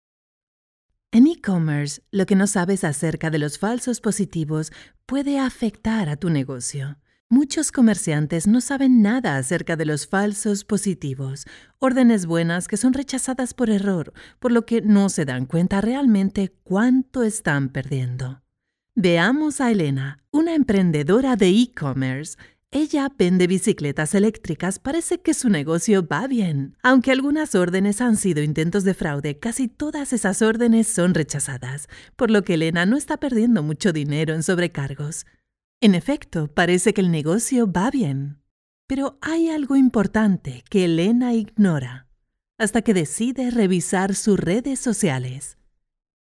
Narration Voiceover Demos - Spanish Female Voiceover Artist Narration Voiceover Demos
Warm, engaging narration voice-over demos for documentaries, audiobooks, training and storytelling.